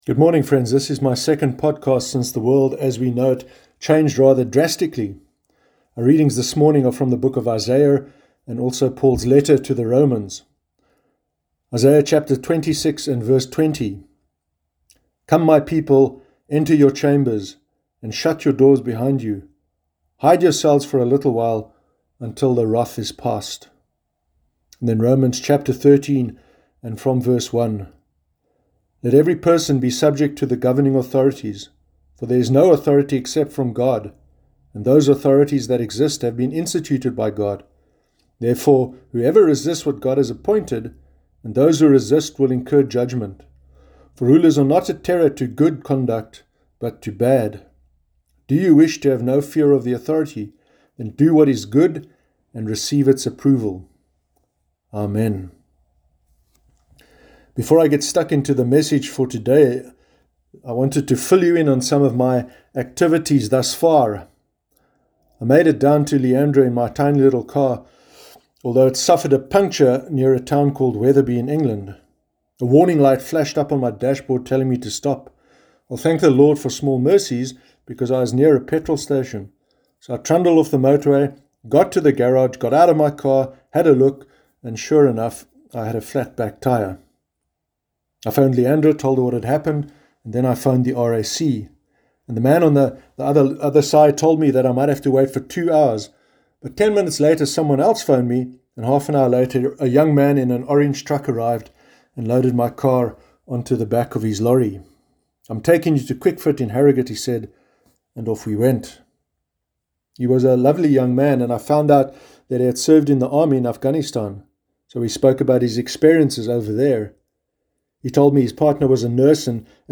Sermon Sunday 29 March 2020